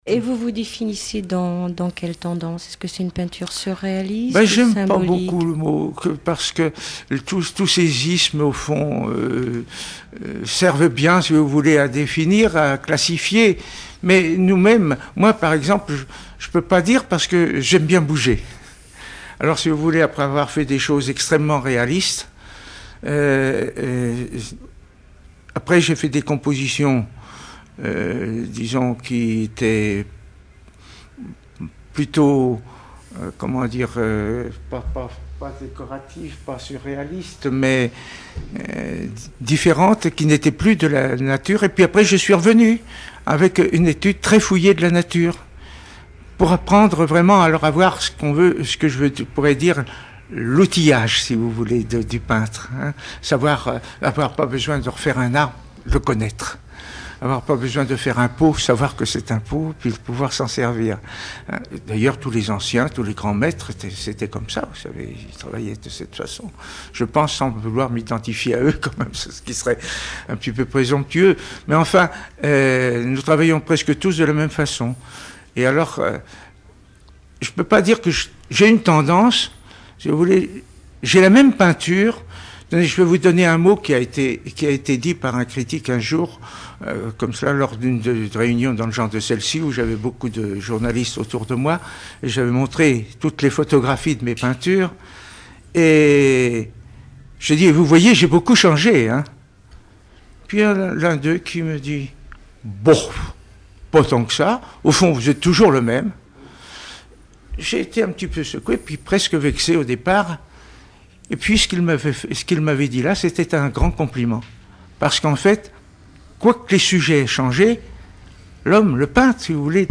Enquête Alouette FM numérisation d'émissions par EthnoDoc
Catégorie Témoignage